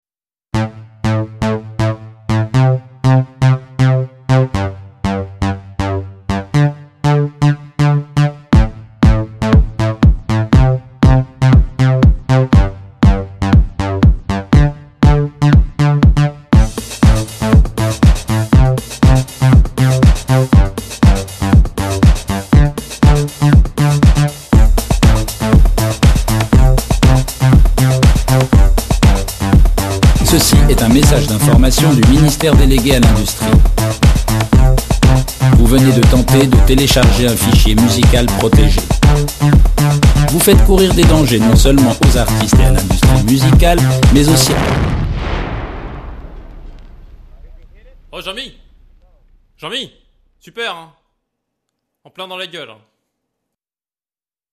Se faizant quelques DJ ont fait des remixes parodiques
de sa voix et ça donne quelques petites perles